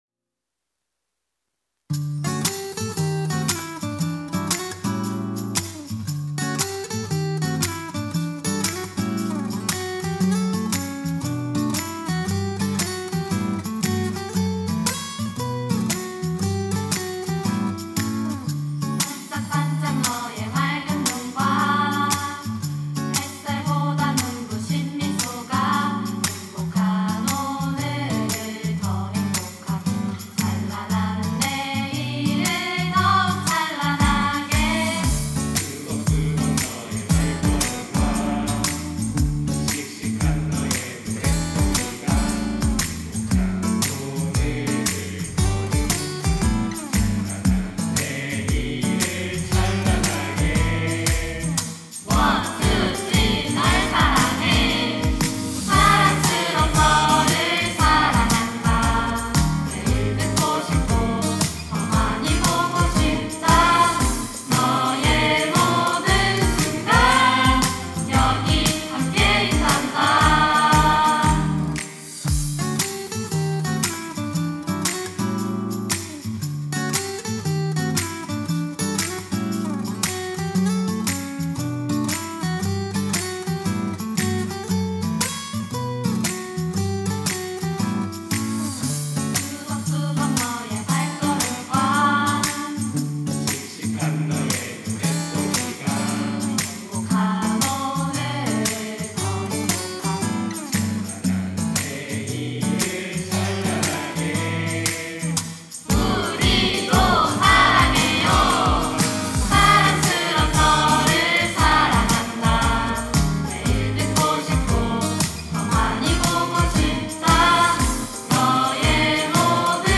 특송과 특주 - 사랑해
이름 청년부 29기